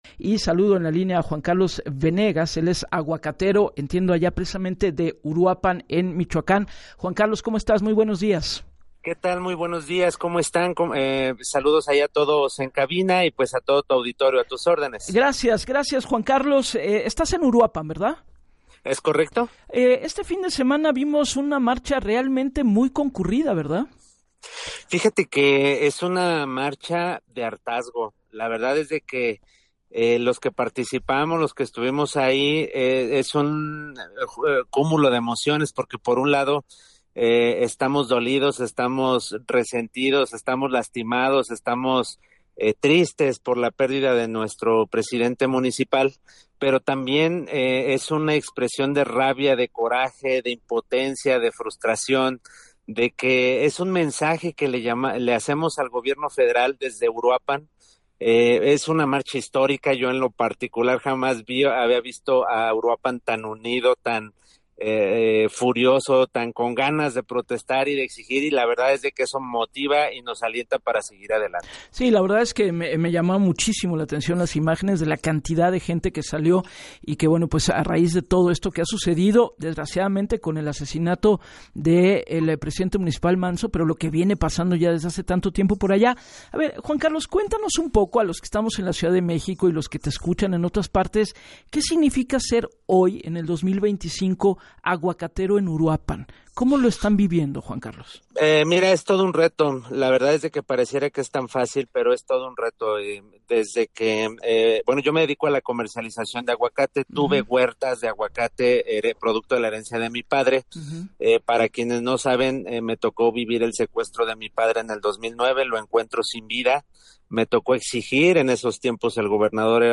En entrevista para “Así las Cosas” con Gabriela Warkentin